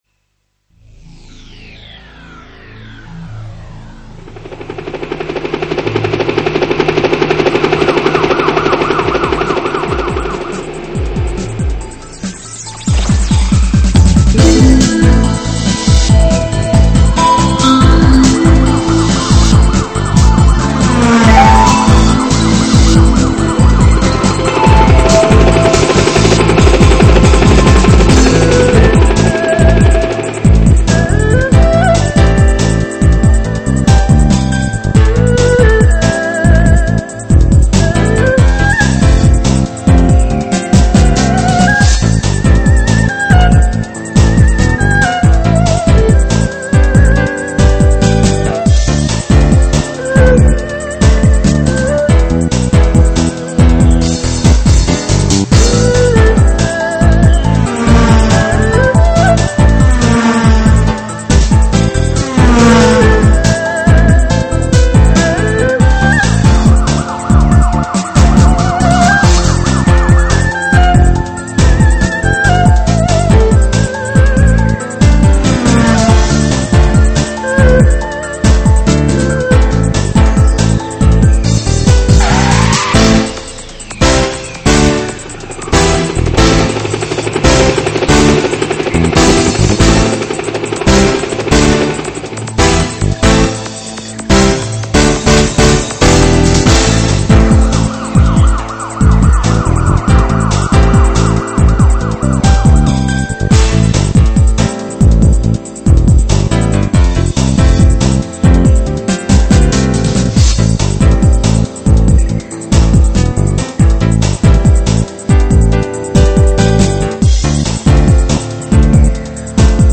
二胡